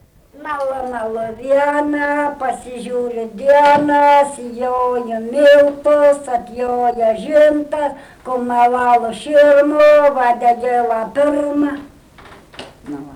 daina, kalendorinių apeigų ir darbo
Obeliai
vokalinis